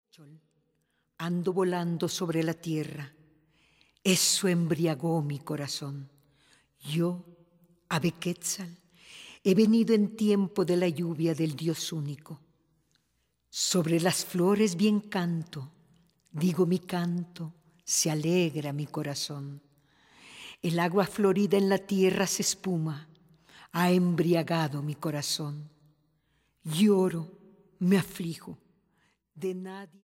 acordeonista
actrice y cantante